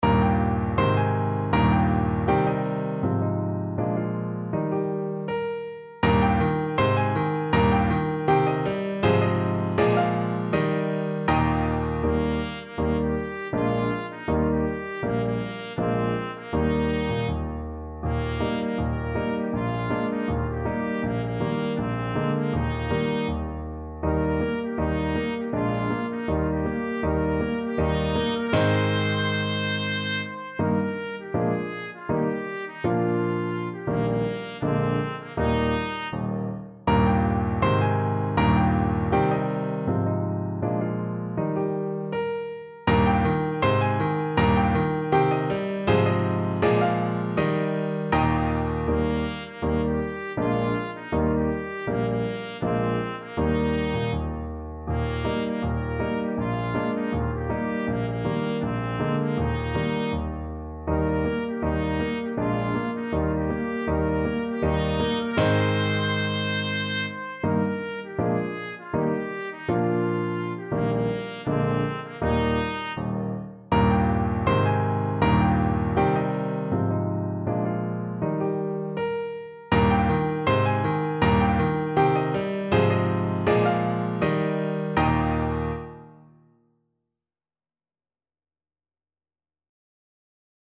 2/4 (View more 2/4 Music)
Bb4-C6
Andante